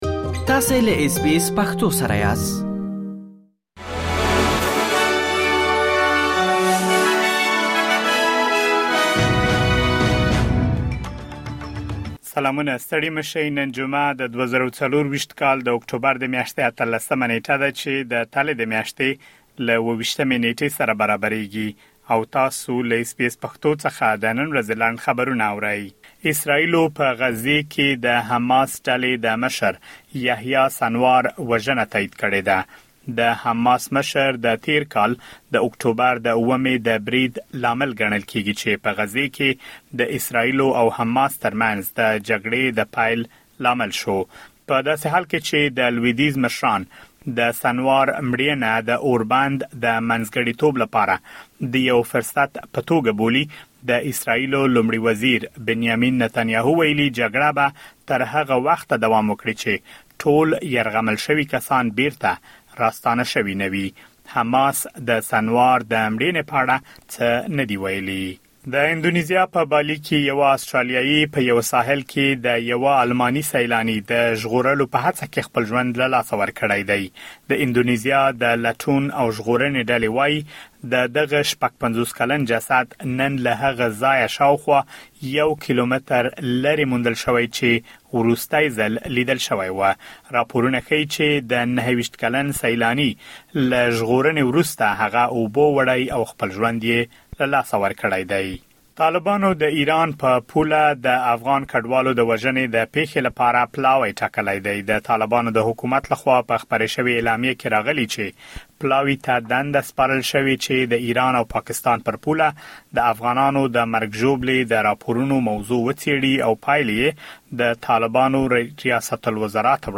د اس بي اس پښتو د نن ورځې لنډ خبرونه|۱۸ اکټوبر ۲۰۲۴